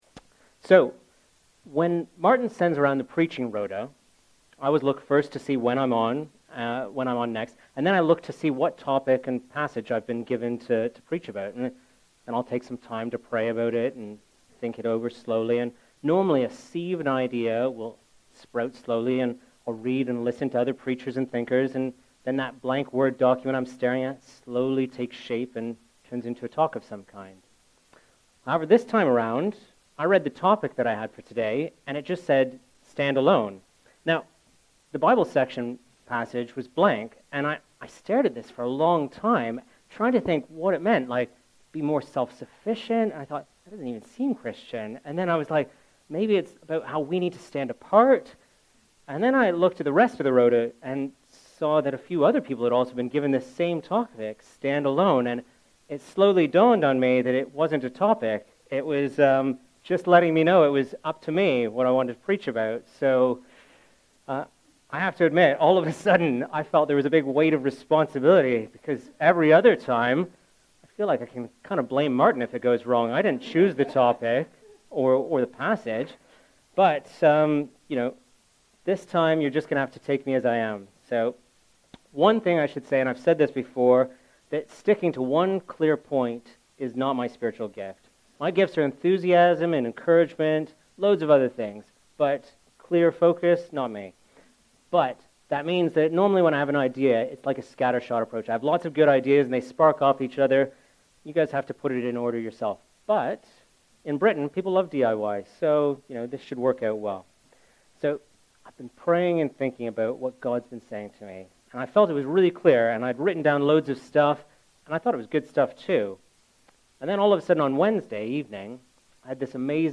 A message from the series "Sunday Morning." The church is Gods plan for our local community and He has positioned us to play a crucial role in its transformation and blessing.